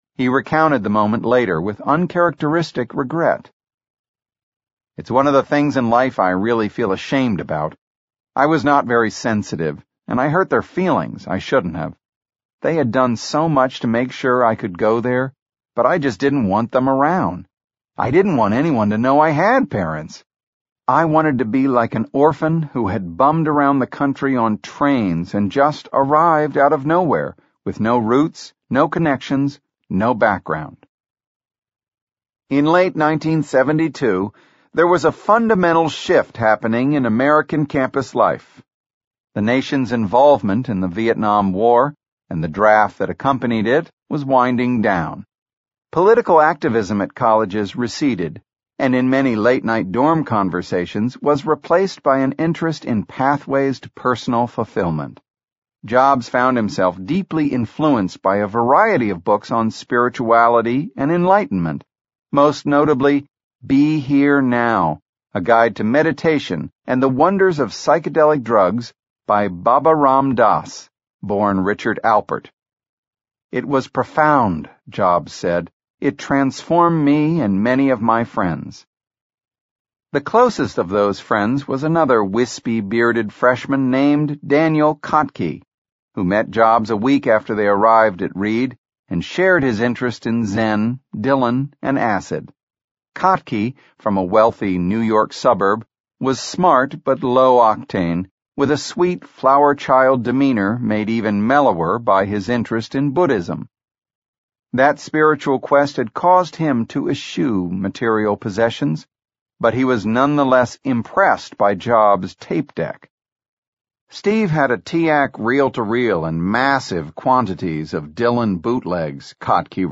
在线英语听力室乔布斯传 第31期:非里德学院不读(2)的听力文件下载,《乔布斯传》双语有声读物栏目，通过英语音频MP3和中英双语字幕，来帮助英语学习者提高英语听说能力。
本栏目纯正的英语发音，以及完整的传记内容，详细描述了乔布斯的一生，是学习英语的必备材料。